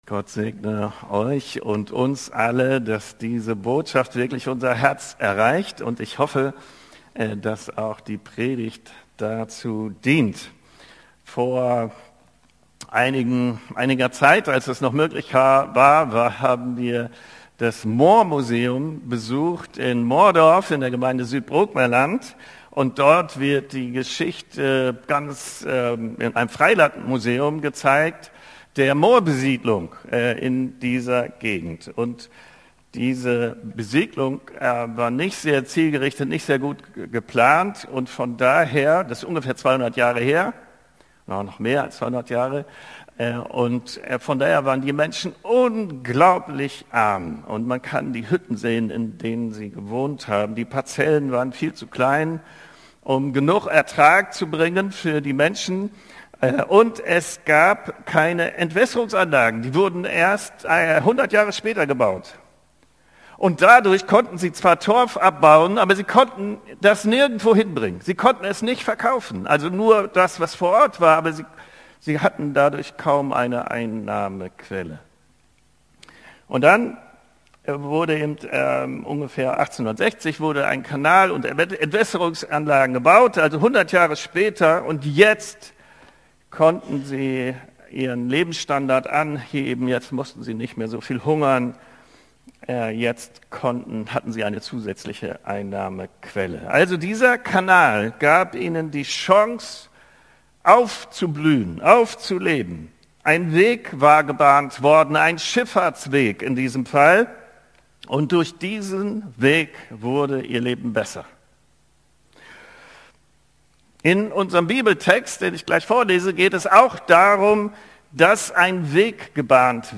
Predigtserie: Leitsätze der Gemeinde